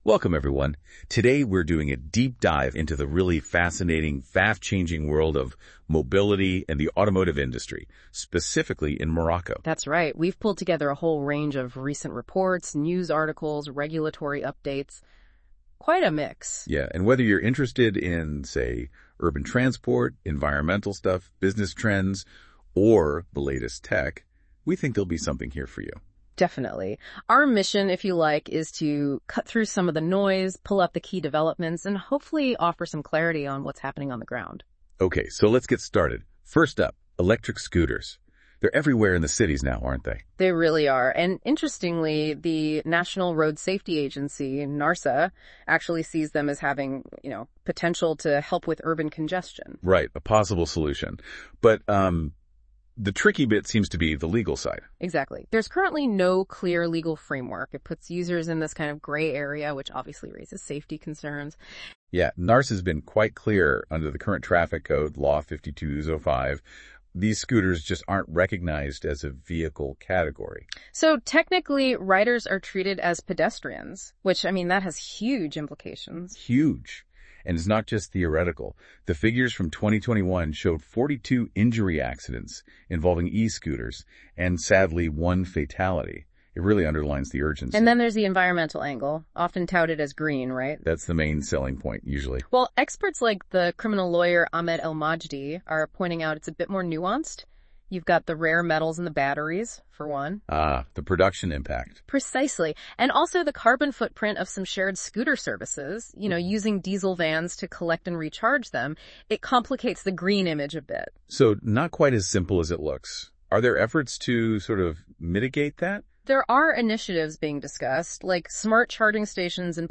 Les chroniqueurs de la Web Radio R212 ont lus attentivement l'hebdomadaire économique de L'ODJ Média et ils en ont débattu dans ce podcast